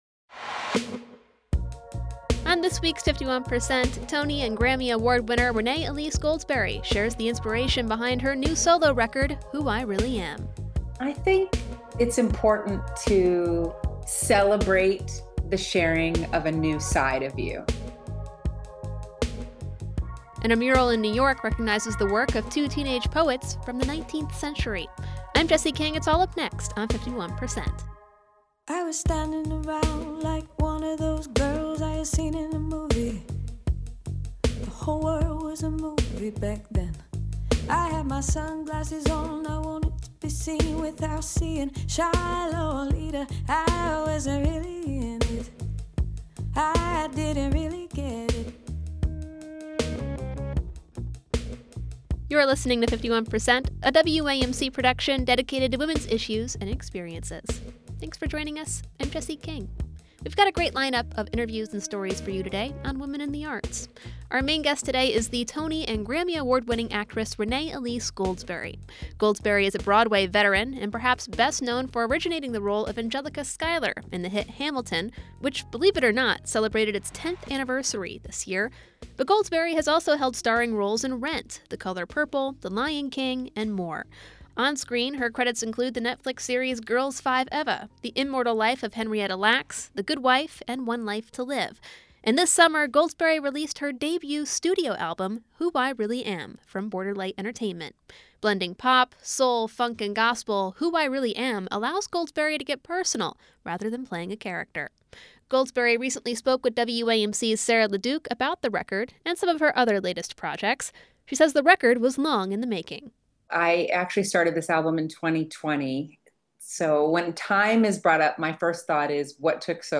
On this week’s 51%, we hear from Tony and Grammy Award-winning actress and singer Renée Elise Goldsberry about her solo debut record Who I Really Am.
Guest: Renée Elise Goldsberry, actress and singer 51% is a national production of WAMC Northeast Public Radio in Albany, New York.